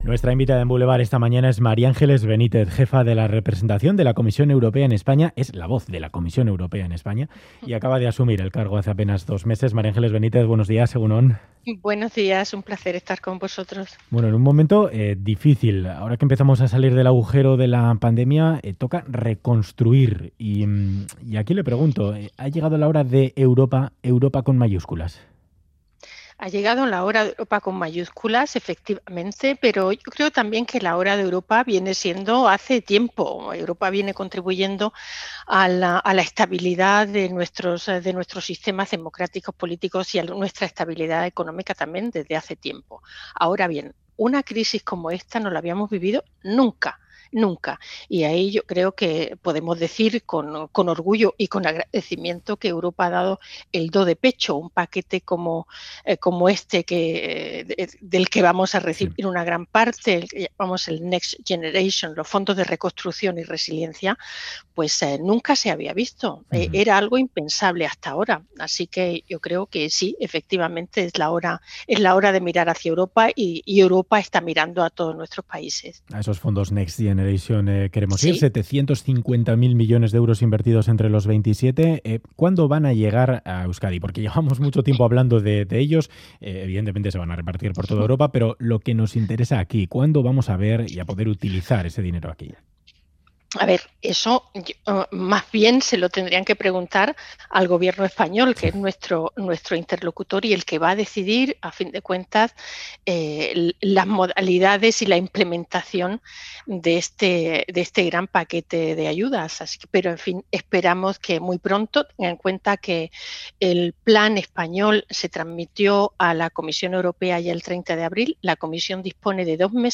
Audio: La jefa de la representación de la Comisión Europea en España asegura que se ha respondido bien a la crisis con los planes de recuperación Next Generation.